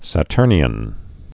(să-tûrnē-ən, sə-)